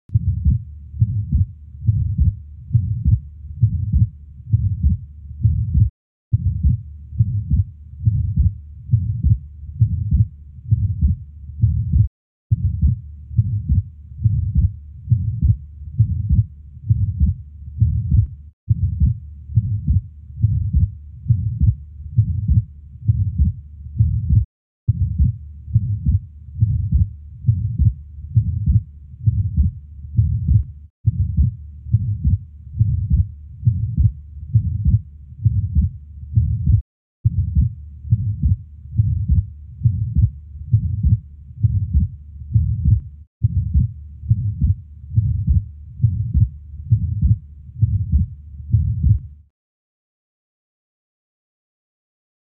Atrial Septal Defect Lower left sternal border